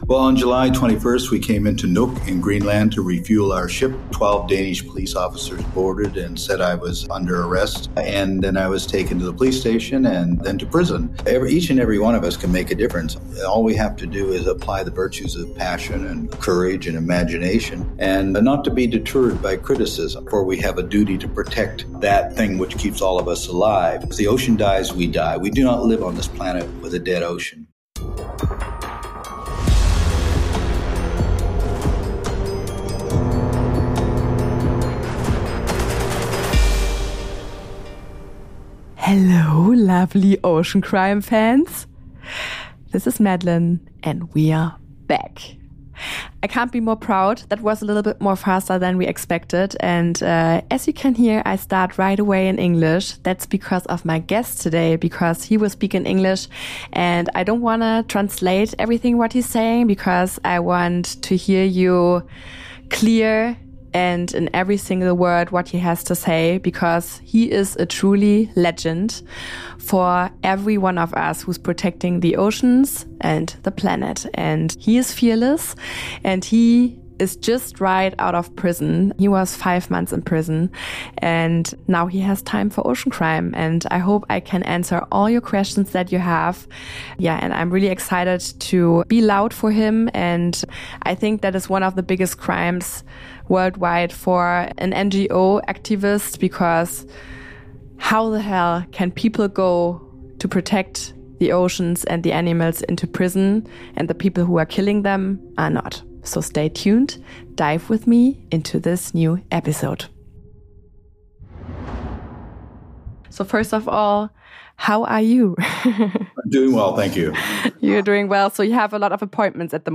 Freshly released from a five-month imprisonment in Nuuk, Greenland, Captain Watson shares his compelling story of resilience, his ongoing fight for marine conservation, and the challenges he faced behind bars. From the events leading to his arrest, the realities of prison life, and the accusations made by Japanese authorities, to his unwavering dedication to protecting the oceans, this interview offers an intimate look at the man behind the mission.
Tune in now for an unfiltered, powerful conversation about courage, activism, and the relentless pursuit of justice for our oceans.